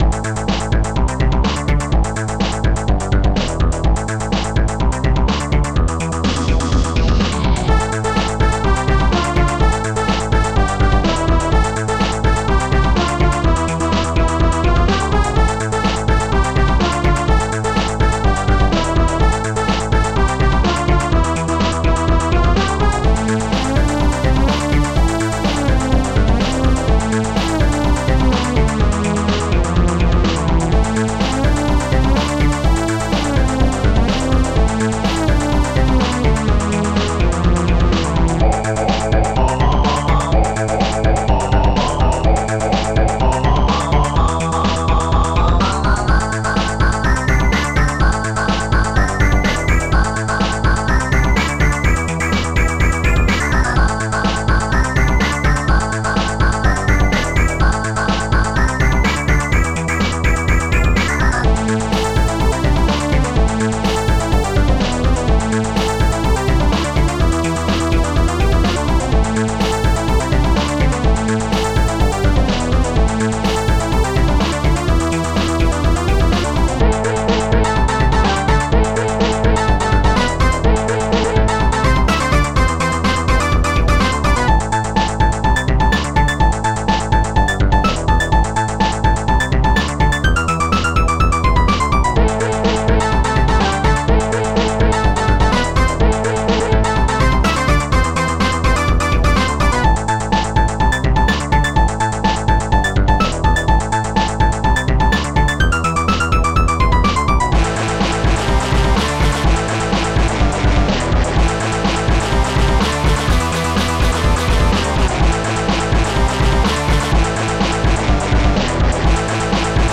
SoundTracker Module